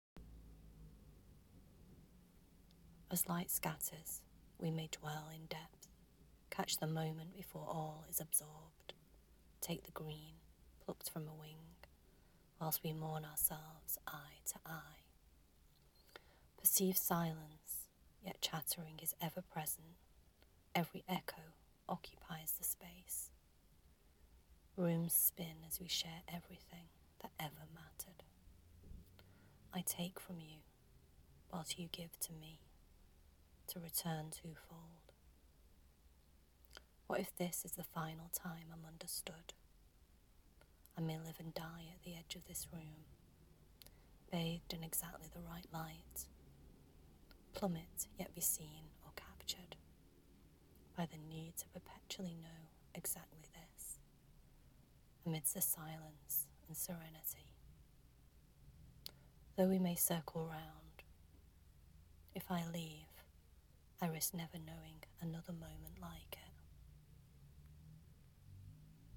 a thoughtful and well expressed composition, your voice adds a nice texture to this